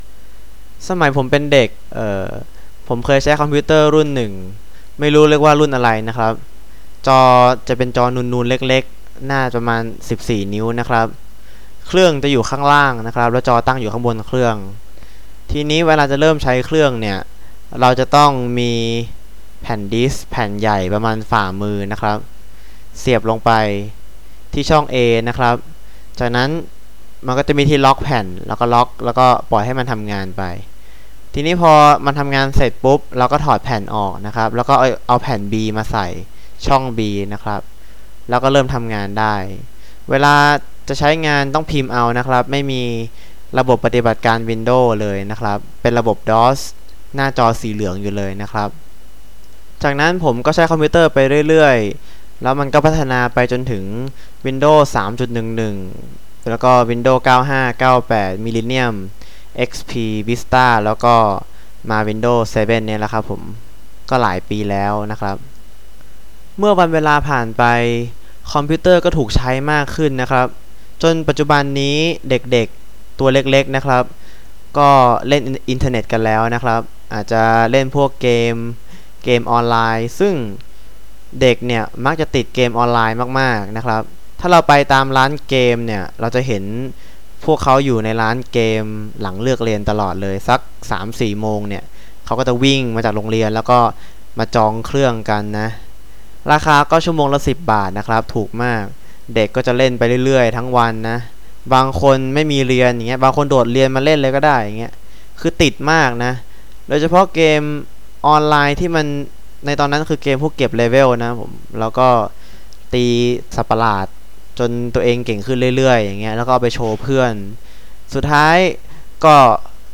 All recordings are unscripted, natural speech and 100% in Thai; they all come with a transcript.
Native speakers